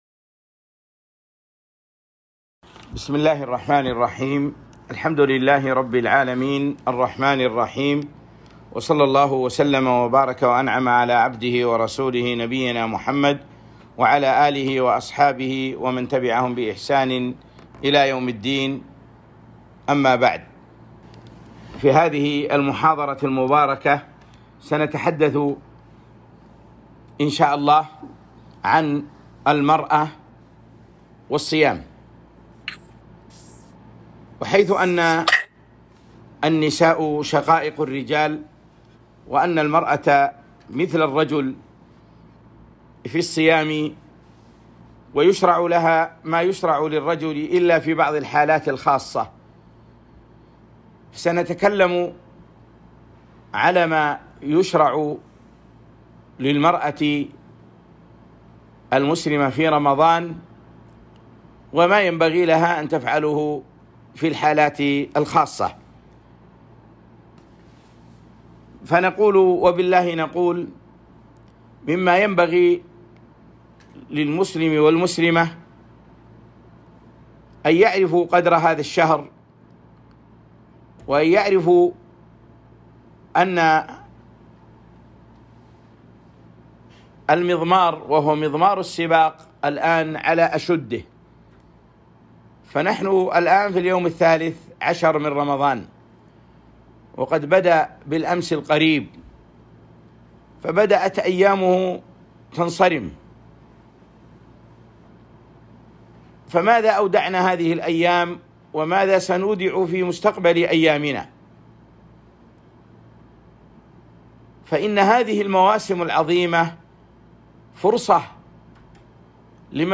محاضرة - المرأة وصيام رمضان